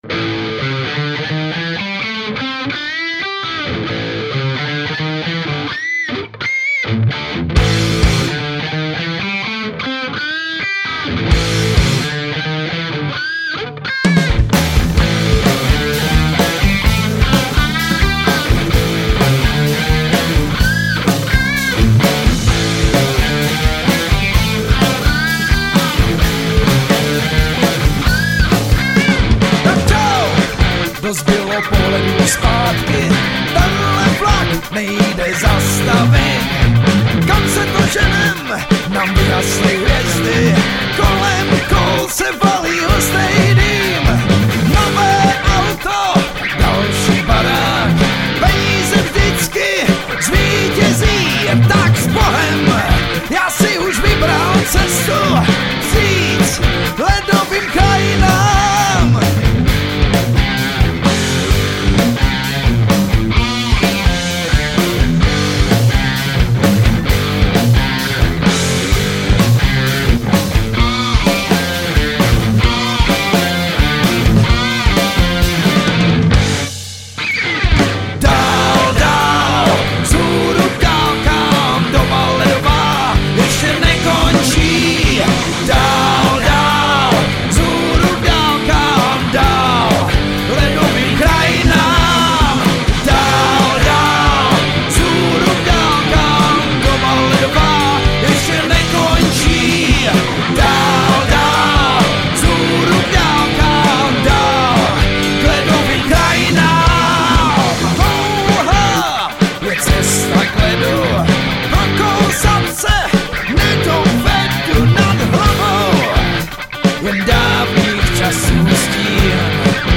Žánr: Rock
Hard-rock 70-90.let s moderním soundem a aranžemi